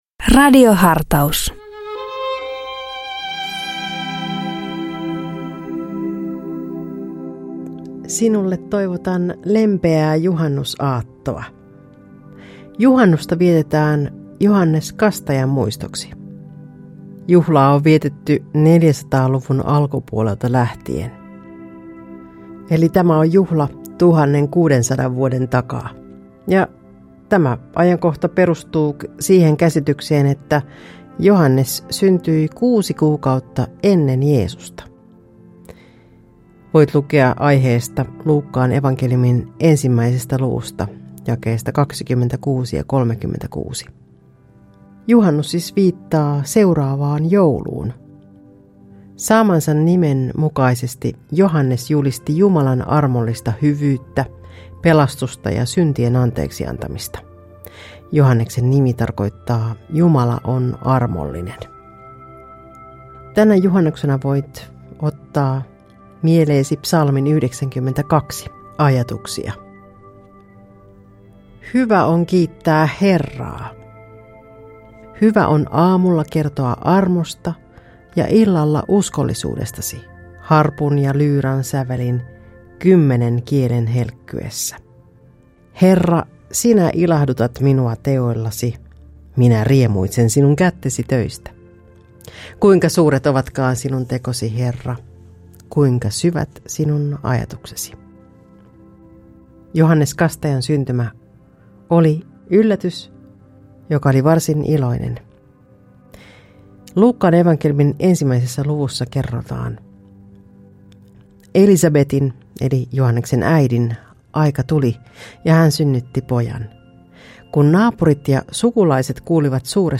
Radio Dei lähettää FM-taajuuksillaan radiohartauden joka arkiaamu kello 7.50. Radiohartaus kuullaan uusintana iltapäivällä kello 16.50. Radio Dein radiohartauksien pitäjinä kuullaan laajaa kirjoa kirkon työntekijöitä sekä maallikoita, jotka tuntevat radioilmaisun omakseen.